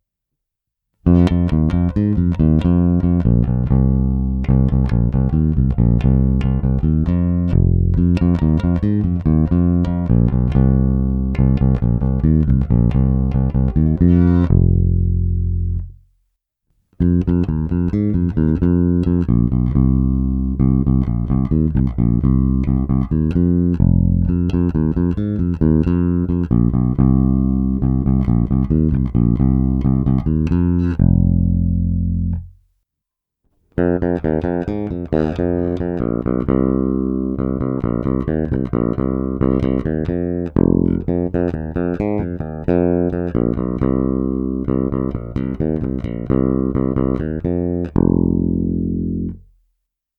Není-li uvedeno jinak, následující nahrávky jsou provedeny rovnou do zvukovky, jen normalizovány a dále ponechány bez jakéhokoli postprocesingu. Korekce byly nastaveny na střední neutrální poloze.